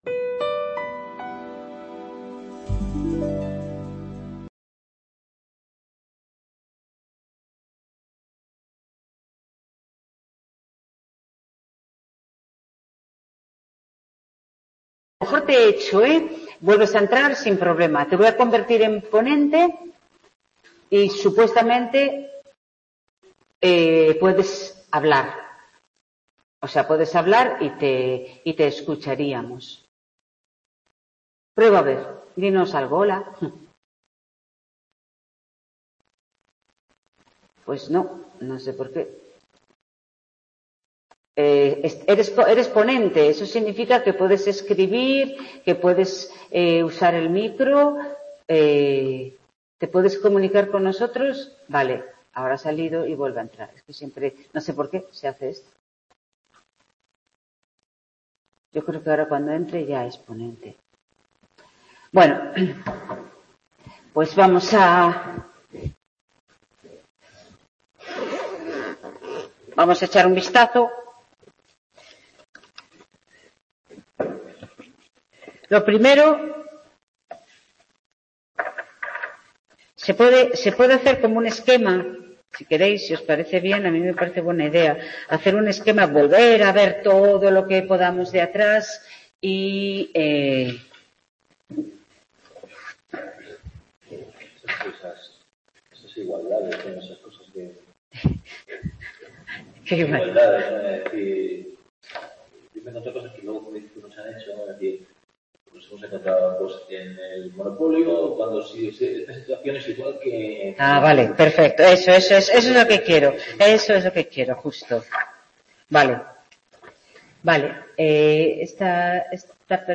Tutoría Microeconmía 2º ADE 15 de enero de 2024
En esta tutoría, la última del curso 2023/2024 se hizo un repaso general de la asignatura y se resolvieron las dudas que plantearon los alumnos y alumnas en el aula.